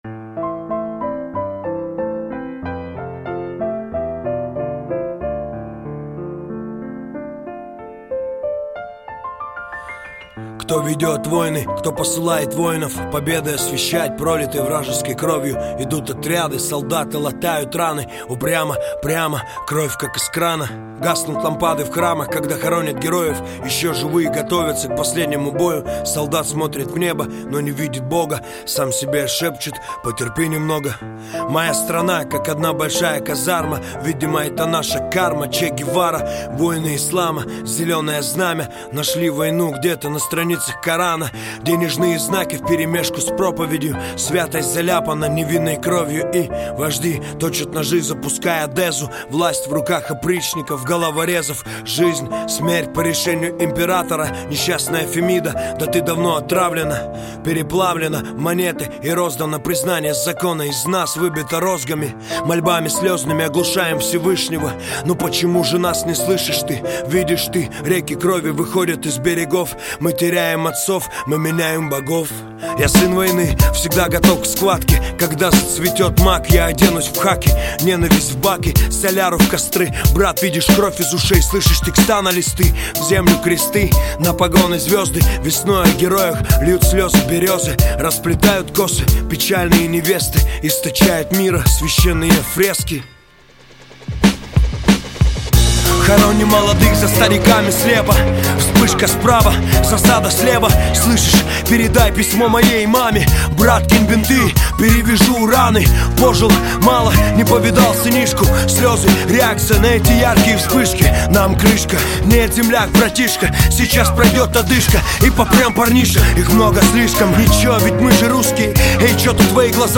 Русский рэп
Жанр: Русский рэп / Хип-хоп